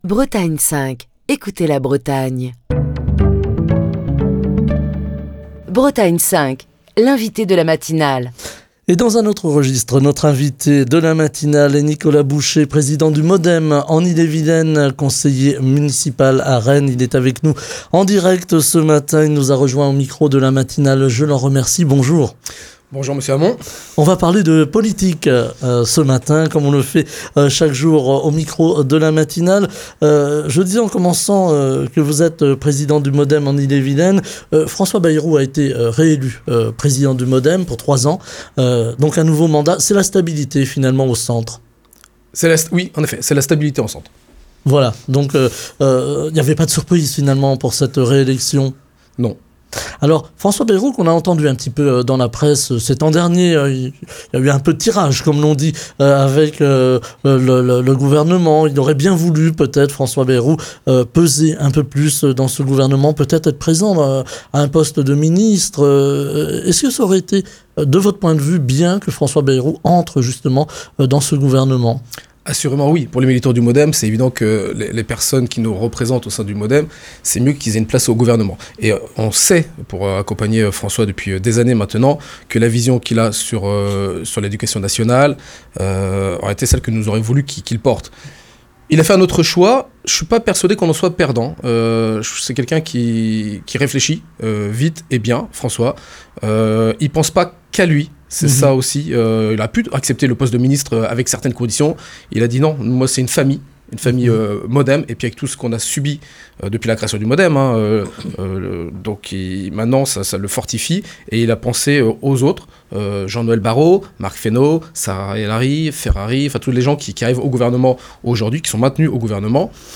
Ce matin, Nicolas Boucher, président du MoDem en Ille-et-Vilaine, conseiller municipal à Rennes est l'invité de Bretagne 5 Matin pour évoquer les élections européennes du 9 juin et les enjeux de l'Union européenne en matière d'économie, d'échanges internationaux, des politiques agricole, sociale et de défense.